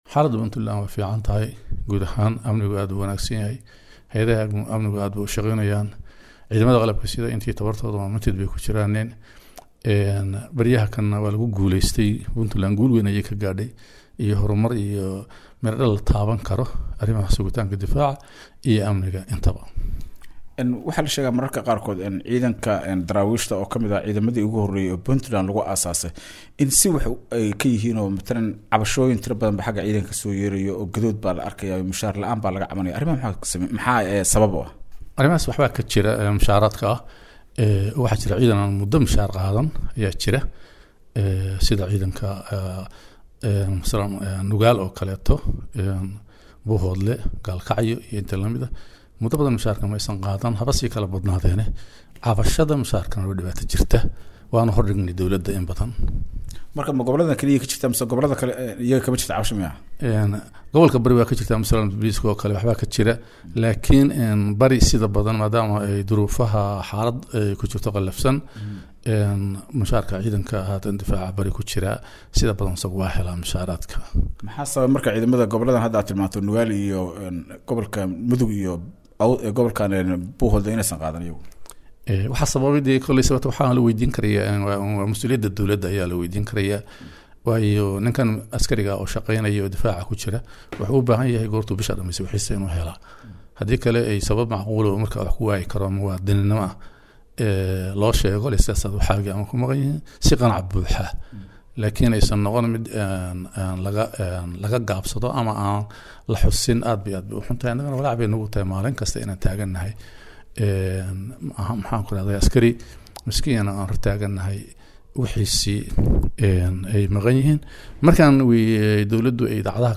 Wareysiga-Siciid-dheere_JFA_Garoowe.mp3